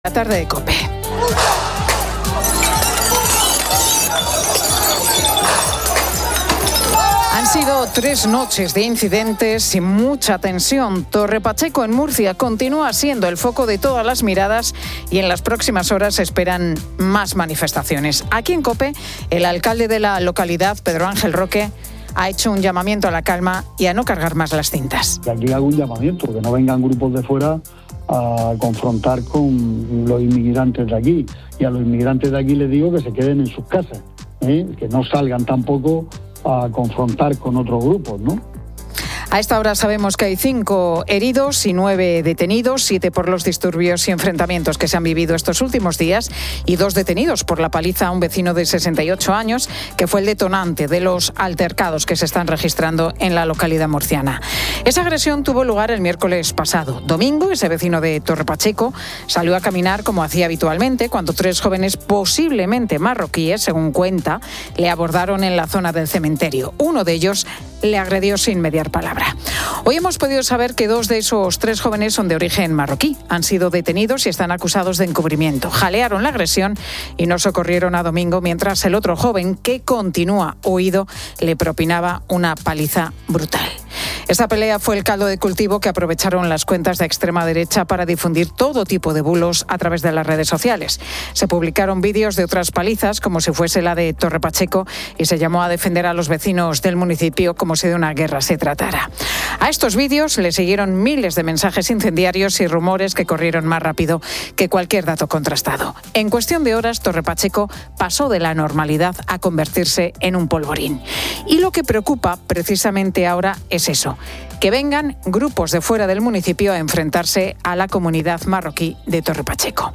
La Tarde 15:00H | 14 JUL 2025 | La Tarde Pilar García Muñiz abre el programa hablando acerca de cómo trabaja la unidad de la policía dedicada al robo de los relojes Rolex. Además, habla del sueño y qué es lo necesario para crear una memoria estable y duradera, tocando temas como la relación entre cantidad y calidad a la hora de descansar.